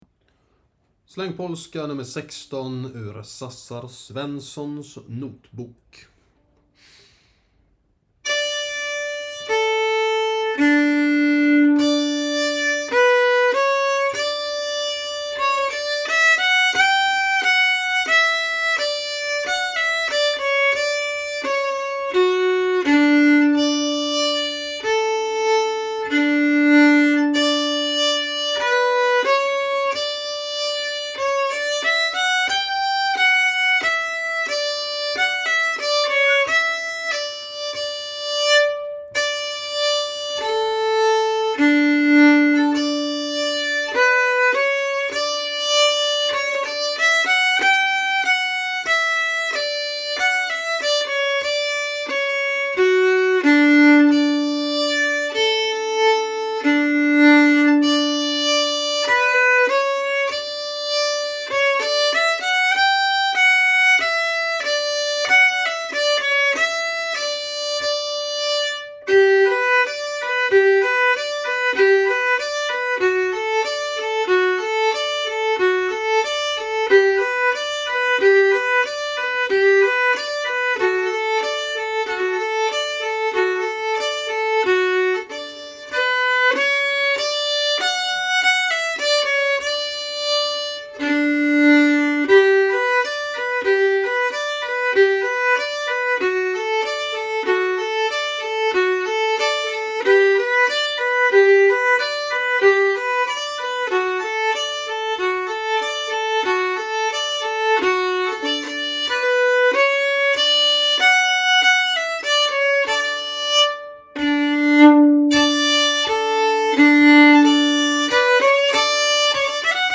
Polonäs